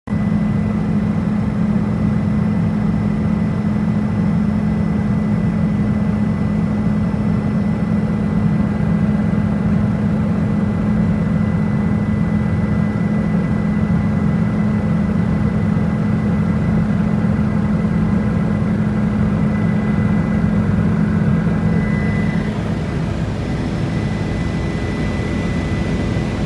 Helicopter ride over Manhattan 🚁❤NYC sound effects free download